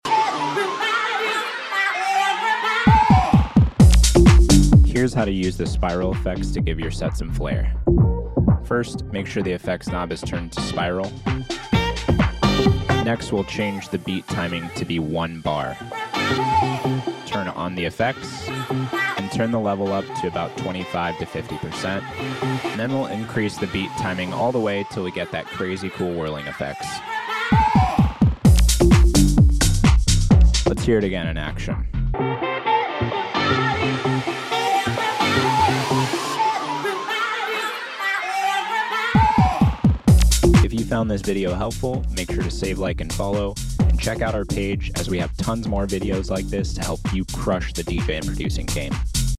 Here's how to make your DJ set stand out using the Spiral fx on any Pioneer DJ mixer or controller to build up a song.